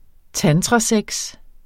Udtale [ ˈtantʁɑˌsεgs ]